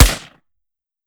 fps_project_1/5.56 M4 Rifle - Gunshot B 001.wav at d65e362539b3b7cbf77d2486b850faf568161f77